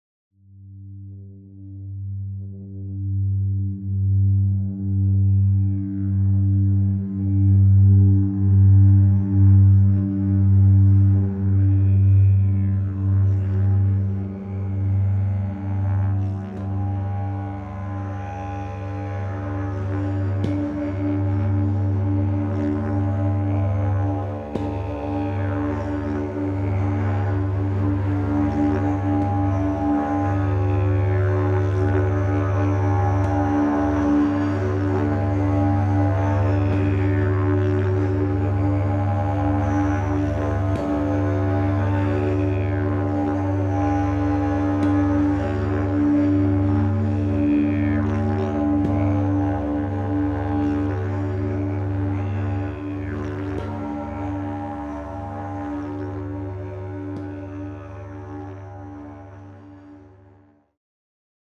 Klangschalen Yoga Musik
u.a. Didgeridoo, Gongs, Klangschalen, Monochord ...